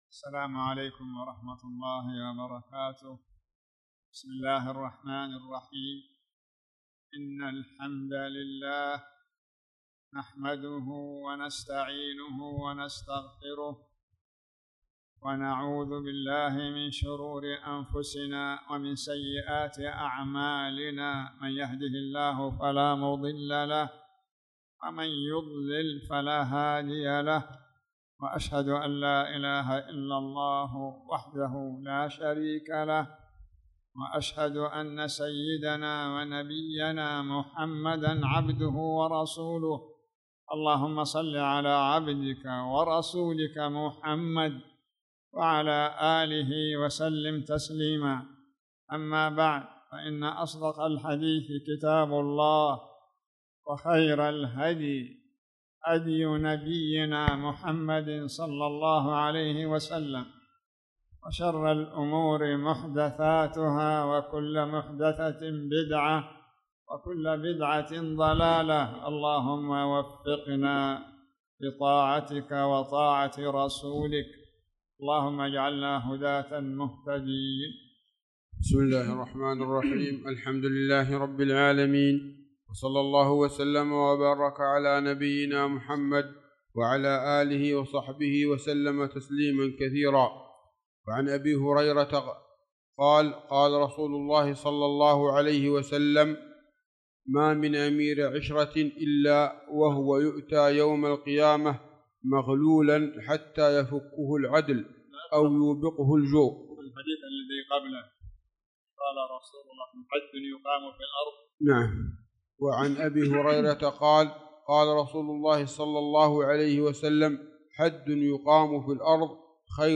تاريخ النشر ١٩ شوال ١٤٣٧ هـ المكان: المسجد الحرام الشيخ